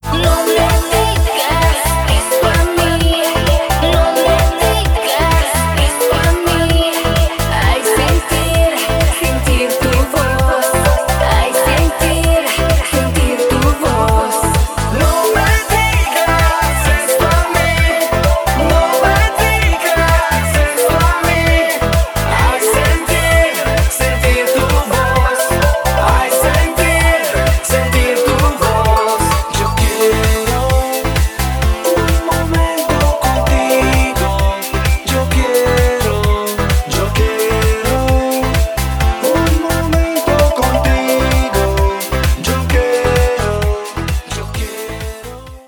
• Качество: 320, Stereo
dance